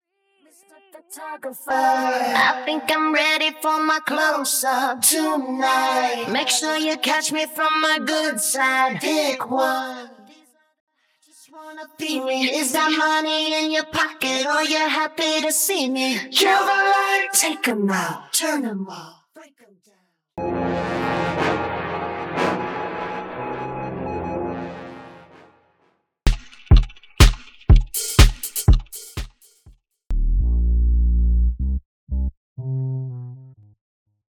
Midline Stem
Percussion & Drums Stem
Subwoofer & Bassline Stem